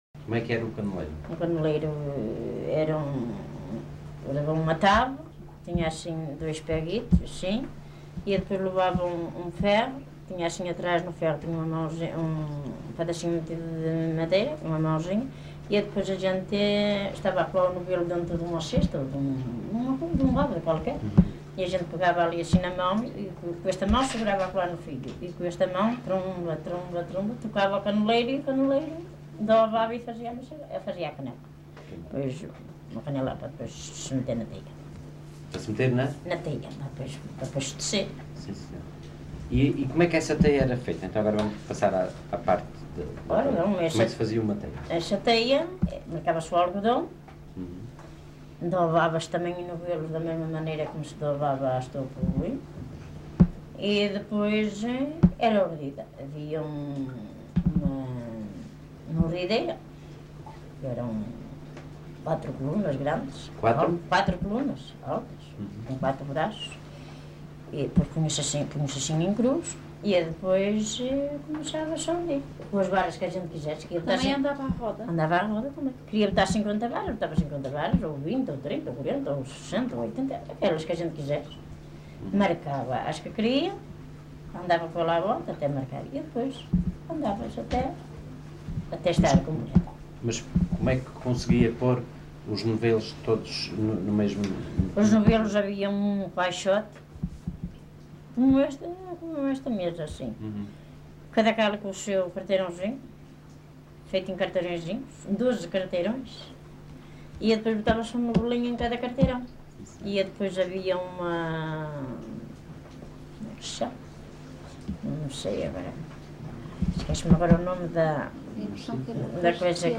LocalidadeFiscal (Amares, Braga)